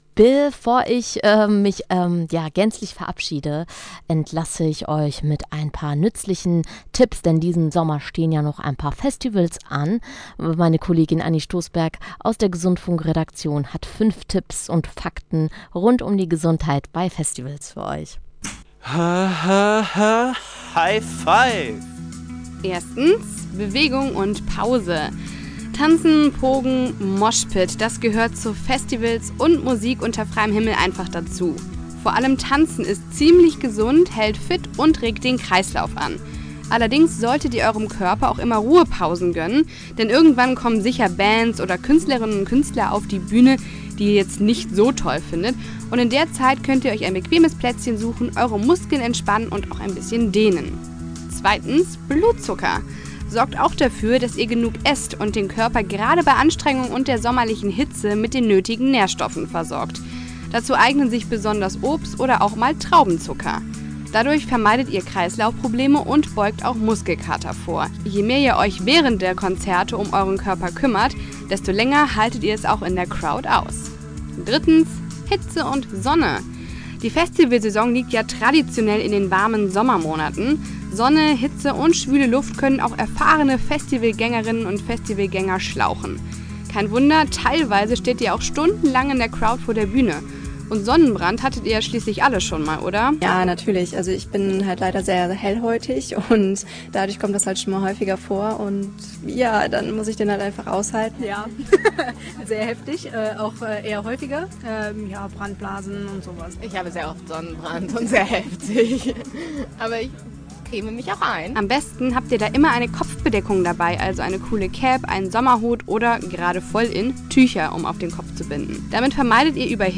Bericht